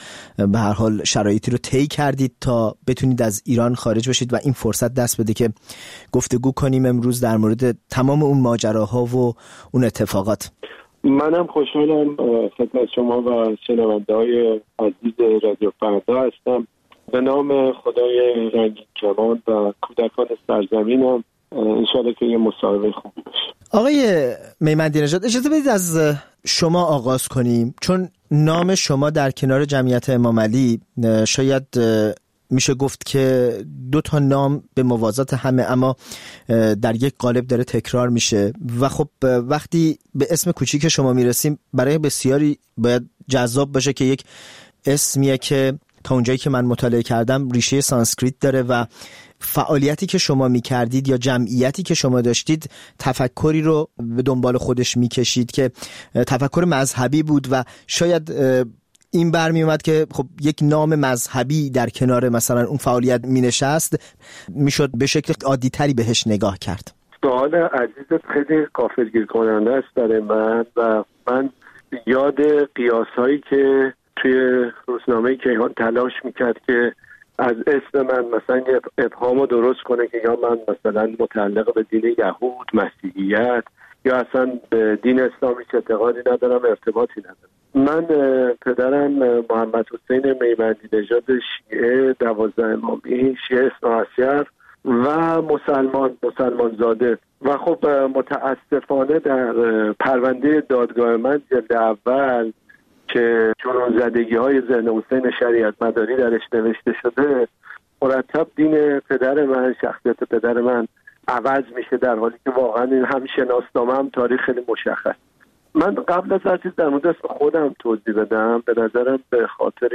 گفت‌وگوی تفصیلی